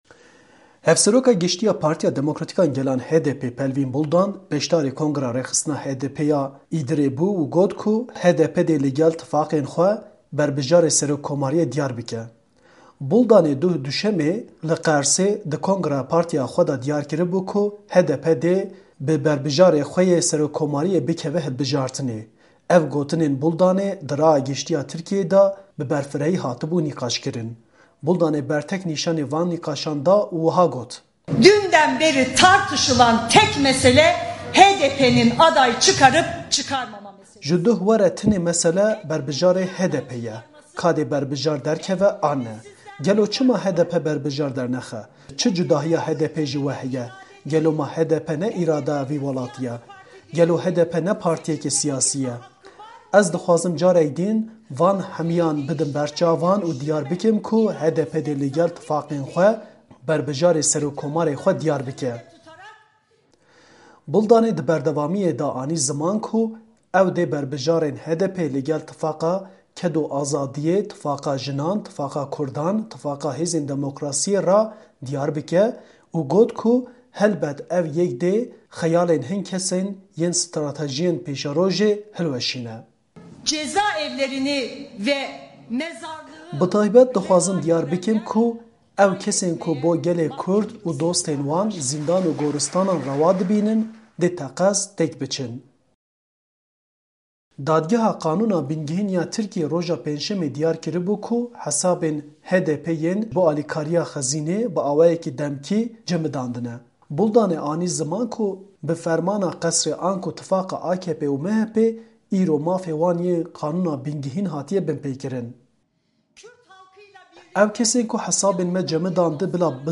Hevseroka Giştî ya Partîya Demokratîk a Gelan (HDP) Pervîn Buldan beşdarî kongreya rêxistina HDPê ya Îdirê bû û got ku HDP dê li gel tifaqên xwe berbijarê serokomarîyê dîyar bike.